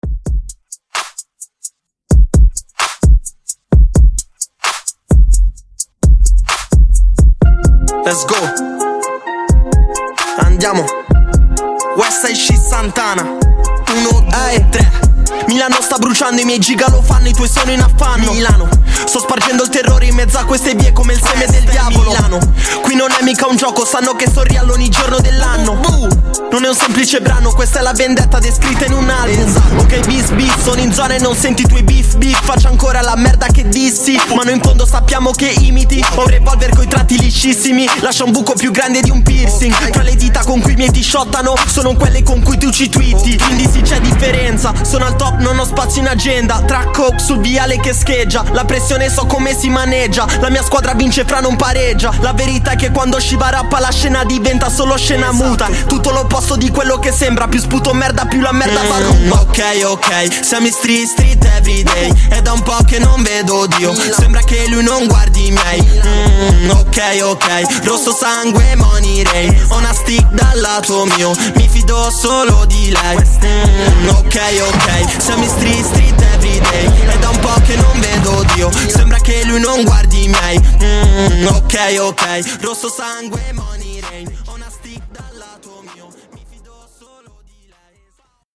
Genres: RE-DRUM , TRAP
Dirty BPM: 130 Time